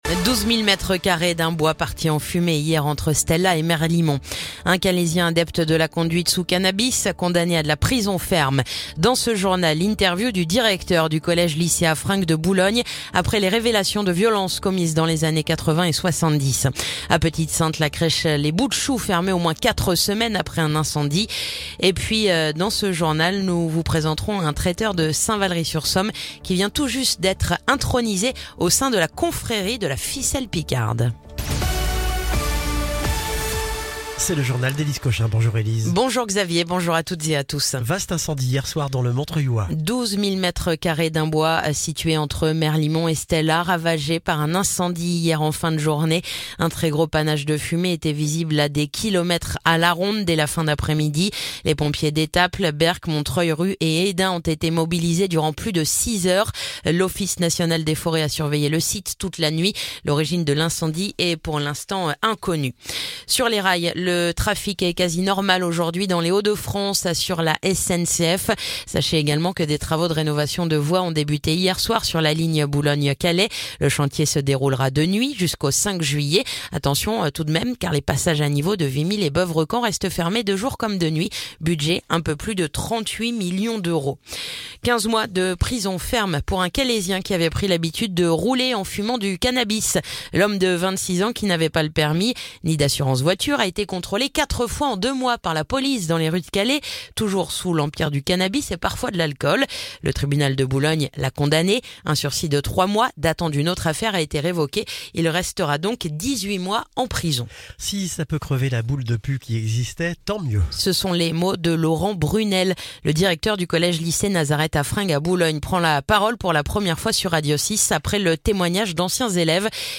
Le journal du mardi 6 mai